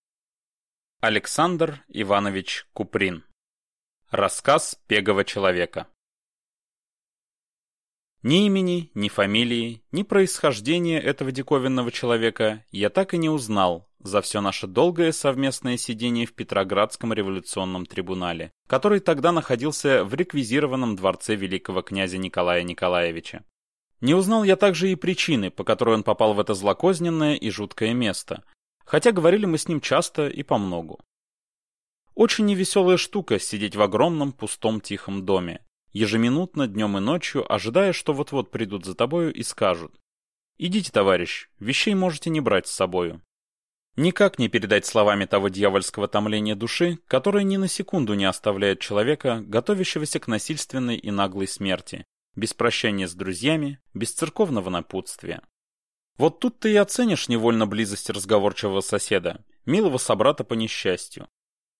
Аудиокнига Рассказ пегого человека | Библиотека аудиокниг